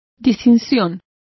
Complete with pronunciation of the translation of difference.